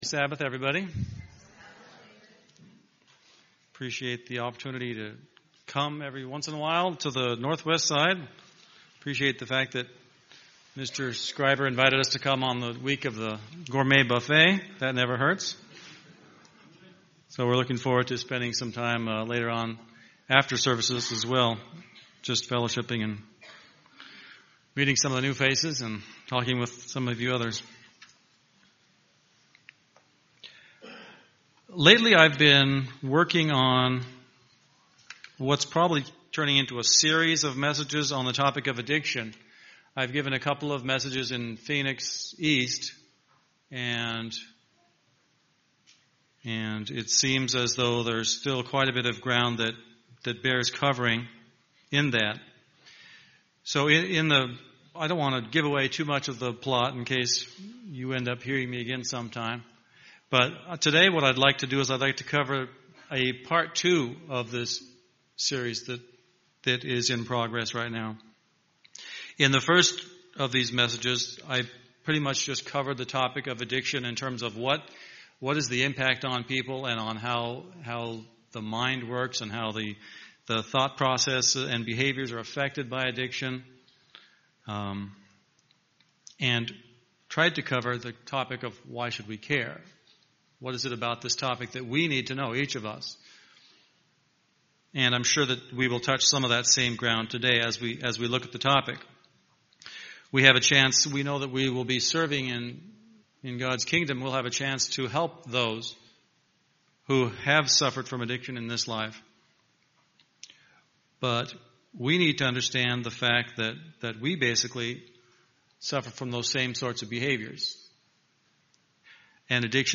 Given in Phoenix East, AZ
UCG Sermon Studying the bible?